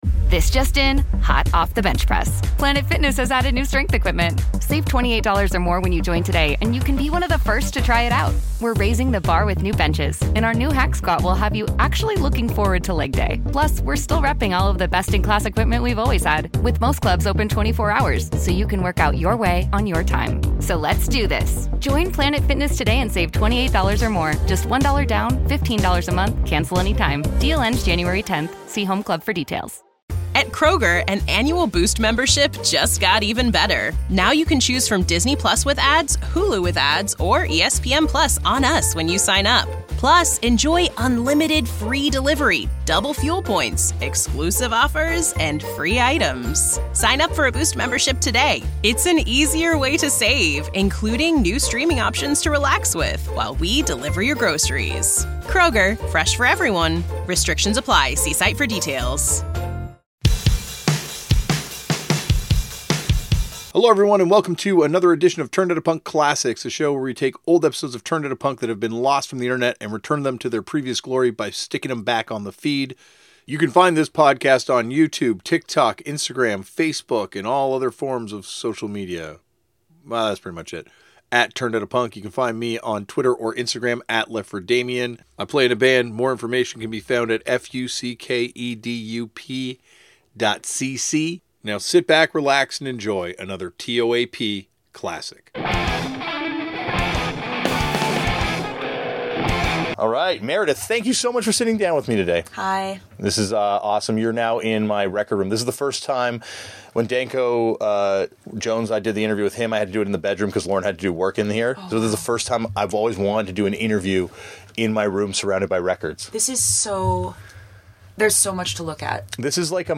Each week, he sits down and chats with an interesting person from various walks of life to find out how their world was influenced and changed by the discovery of a novelty genre that supposedly died out in 1978... PUNK!